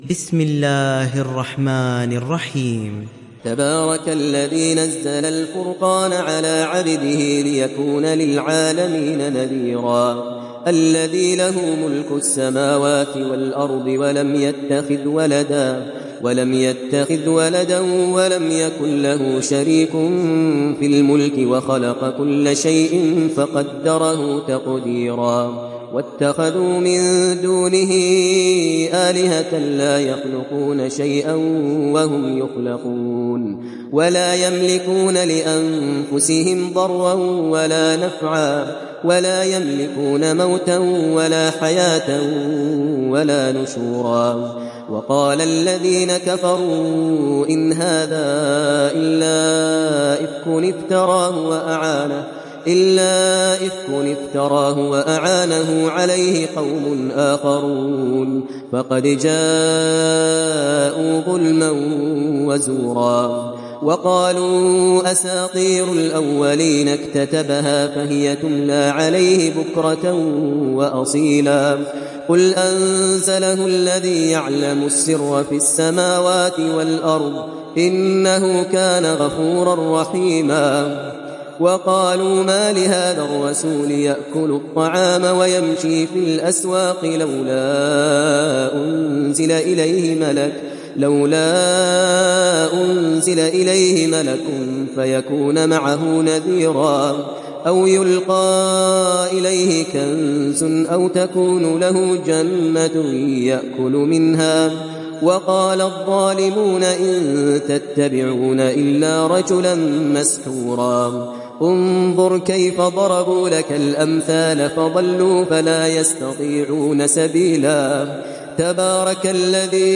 تحميل سورة الفرقان mp3 بصوت ماهر المعيقلي برواية حفص عن عاصم, تحميل استماع القرآن الكريم على الجوال mp3 كاملا بروابط مباشرة وسريعة